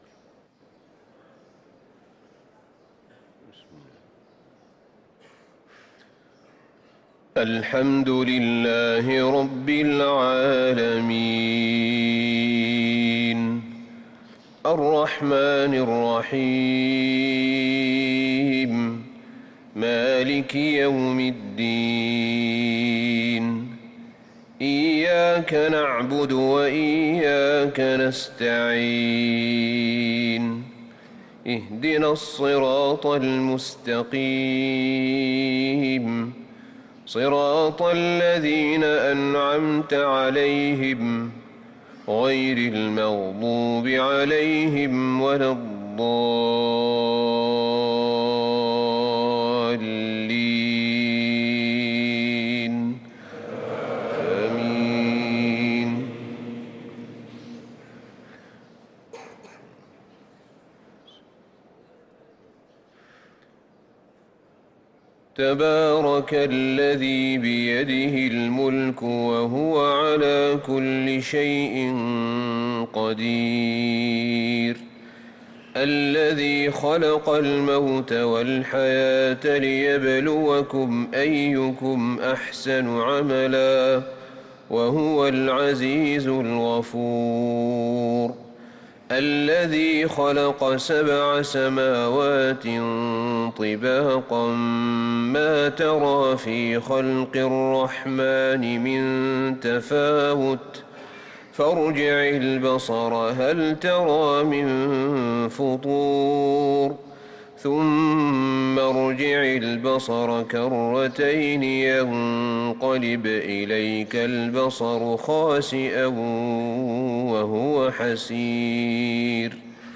صلاة الفجر للقارئ أحمد بن طالب حميد 17 ربيع الآخر 1445 هـ